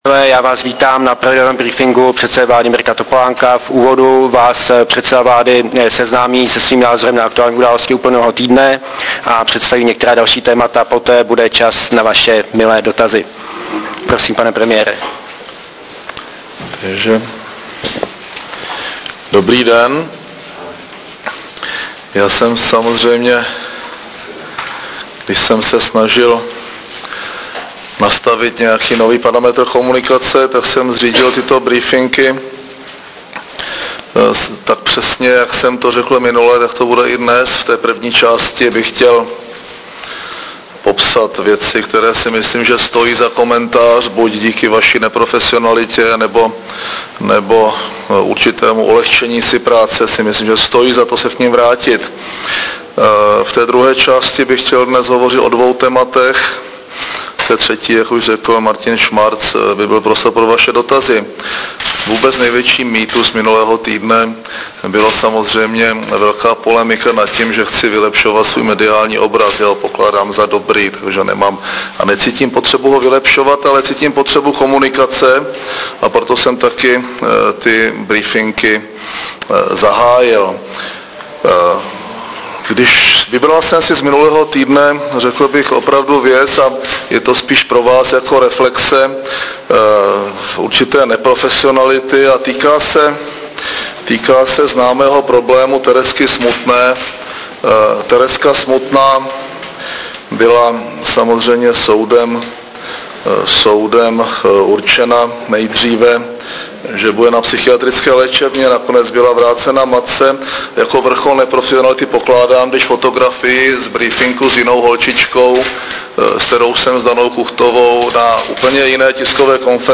Tiskový briefing předsedy vlády ČR Mirka Topolánka k aktuálním otázkám 10. září 2007